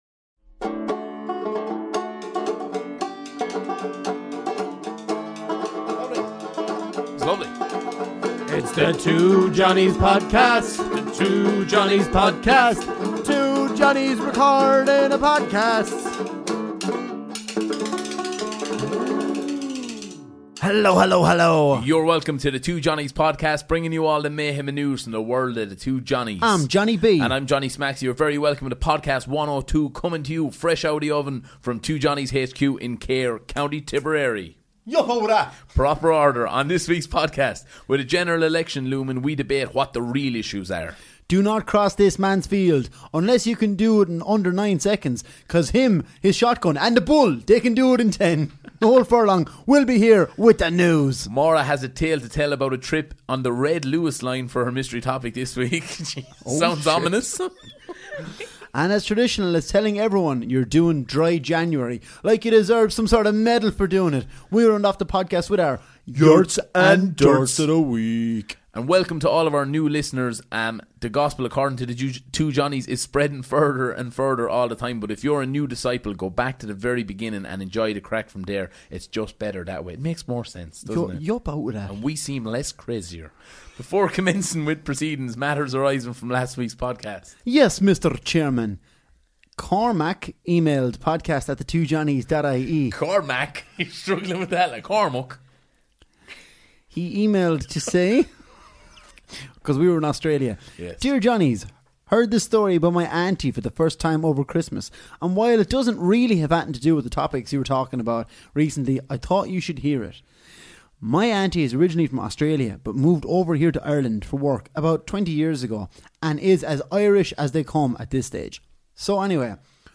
Ireland's favourite comedy duo tackle the big issues, This week: